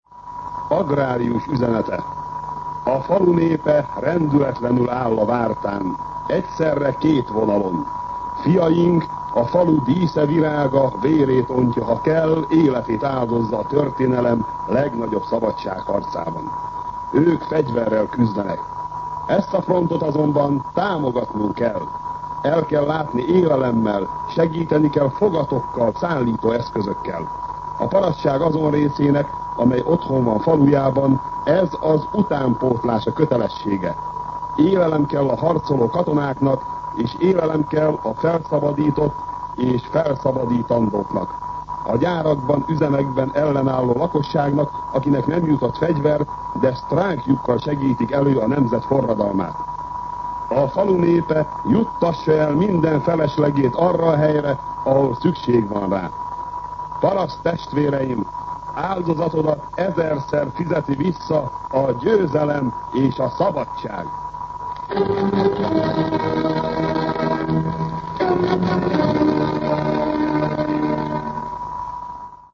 Szignál
MűsorkategóriaKommentár